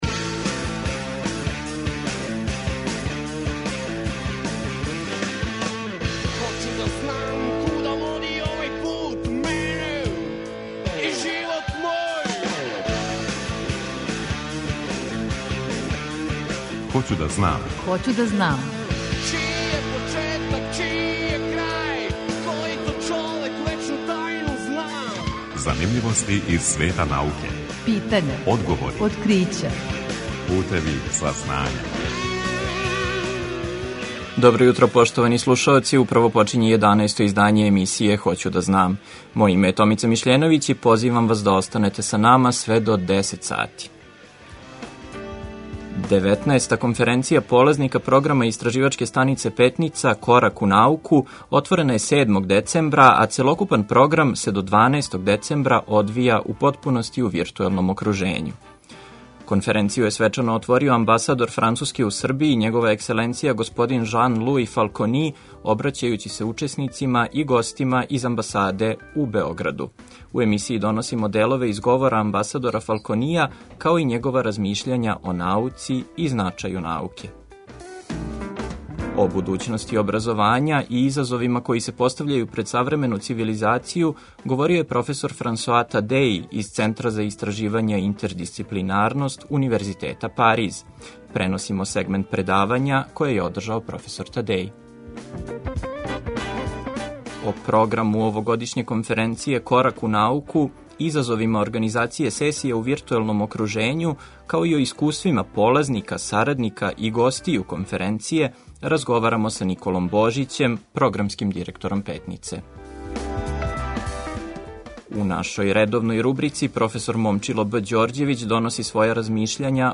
У емисији доносимо делове из говора амбасадора Фалконија, као и његова размишљања о науци и значају науке.